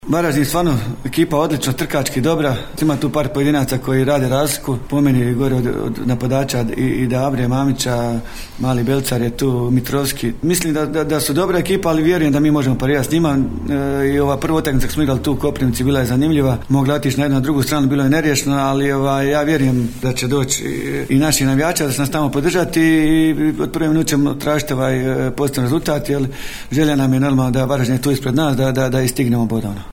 na konferenciji za medije